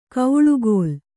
♪ kauḷugōl